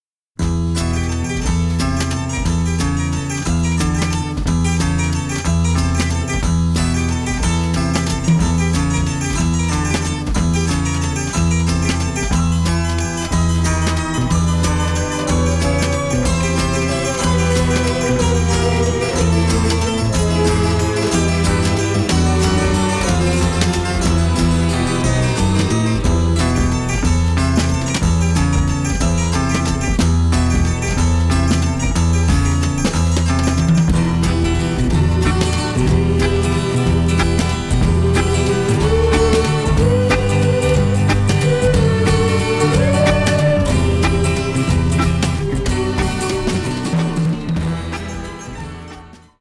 Originalmusik aus den deutschen Kult-Krimis